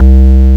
BASS48  01-L.wav